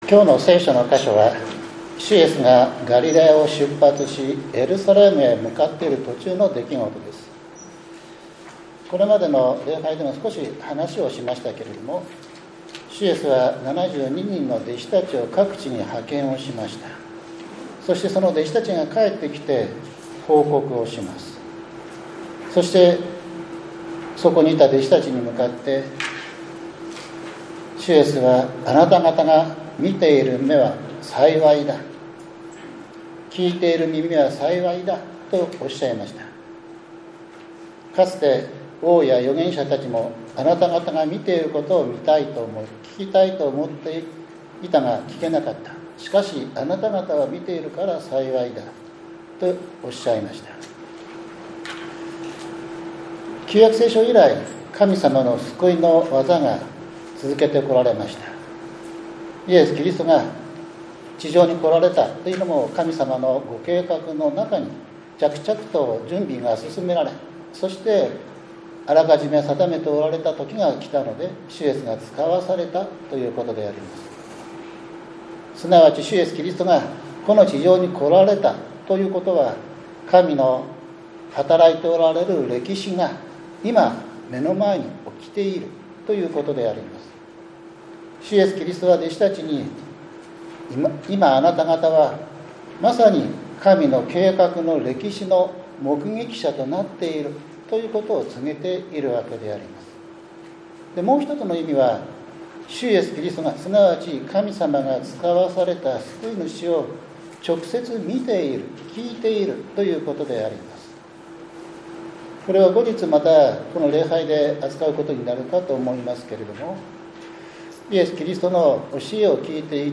７月１３日（日）主日礼拝